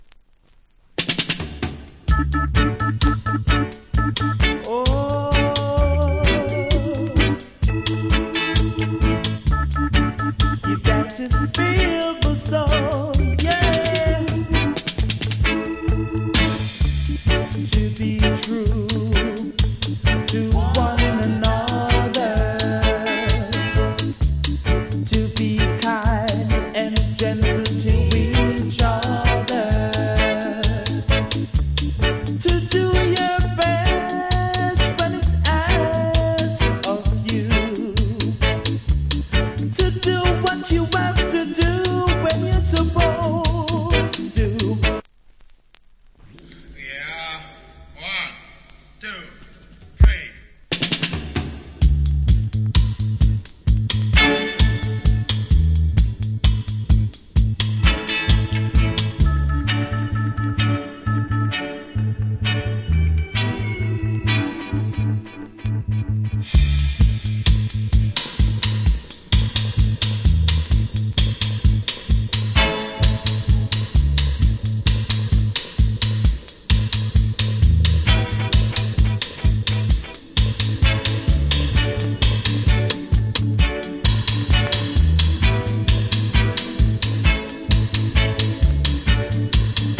Reggae Vocal With DJ.